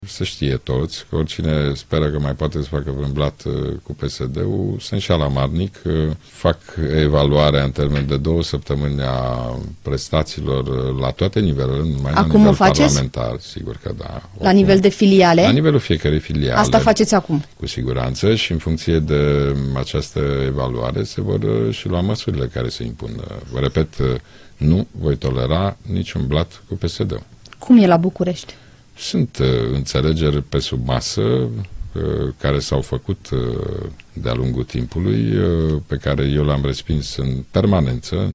Cine face sau a făcut blat cu PSD va plăti, a avertizat Ludovic Orban la Interviurile Europa FM.